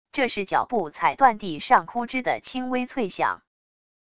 这是脚步踩断地上枯枝的轻微脆响wav音频